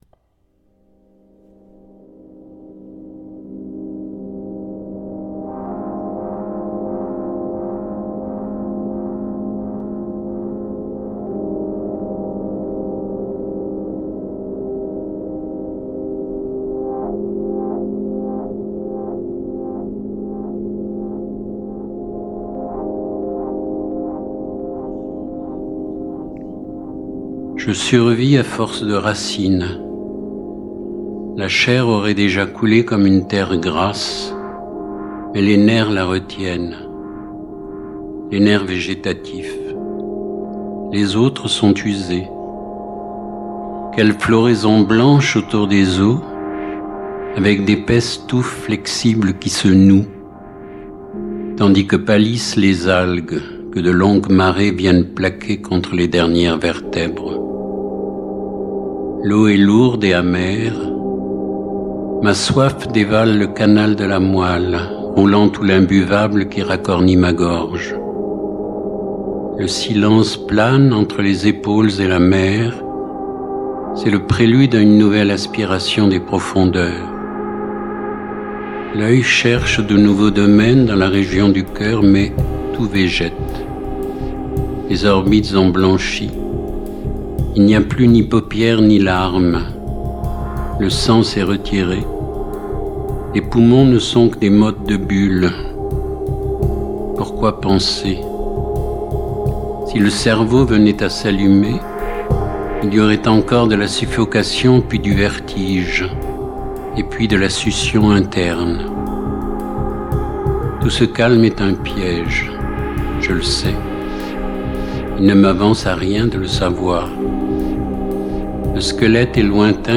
Dans l’occupation momentanée d’espace qui ne nous appartenait pas, où résonnaient voix et bruits de quincaillerie. L’activité ordinaire d’un hall d’hôtel de la place de la gare à Rennes, et l’univers d’auteurs majeurs dans la littérature française bien présents à penser fraîchement par un beau matin de janvier.
Si bien que plus tard dans la foulée de son travail de mixage – et d’atténuation des fragments parasites-, témoin, muet, d’instants, l’envie lui est venue de sortir ses instruments pour une mise en musique de Situation lyrique du corps naturel, qui fait pleinement écho dans le moindre des mots lus par son auteur.